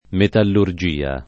metallurgia [ metallur J& a ] s. f.